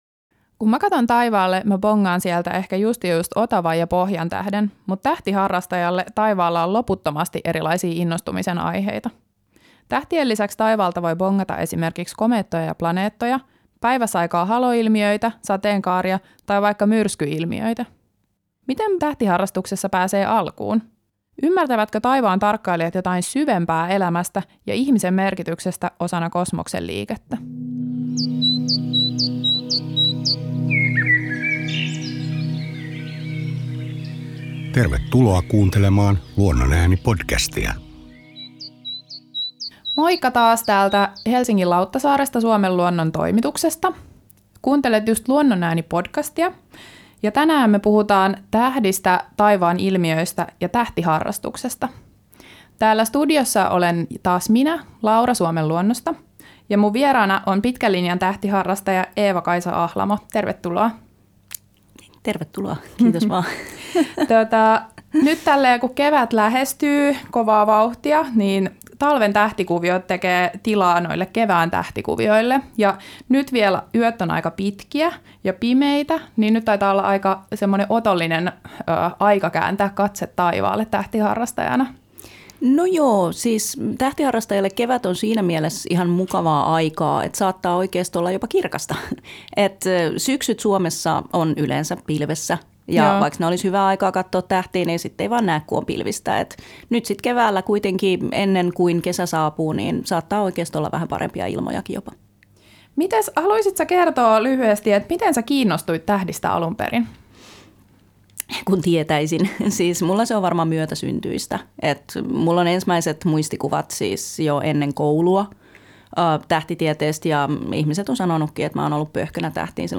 vieraana pitkän linjan tähtiharrastaja